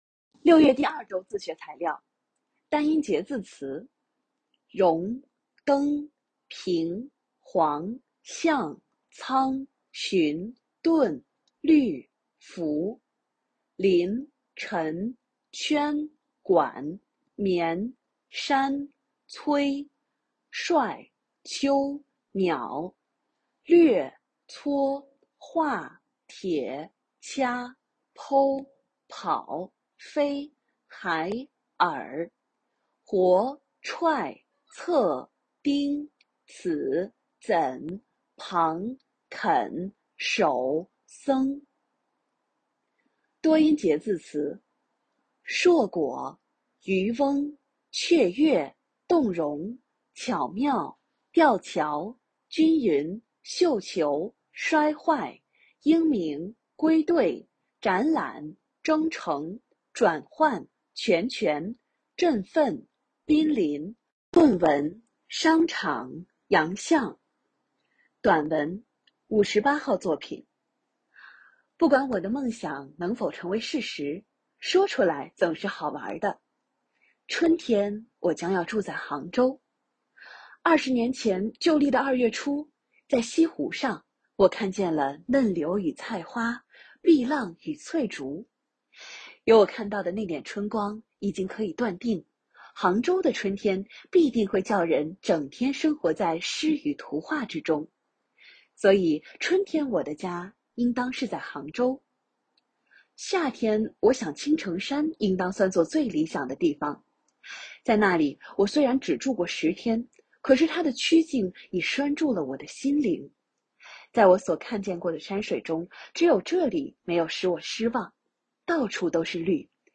领读课件